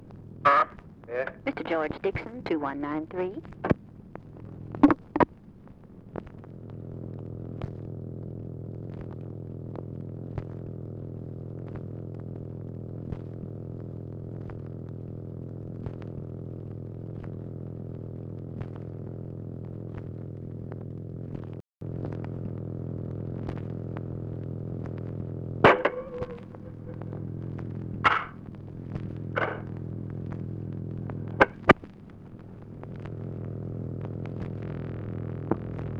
Conversation with OFFICE SECRETARY, November 27, 1963
Secret White House Tapes | Lyndon B. Johnson Presidency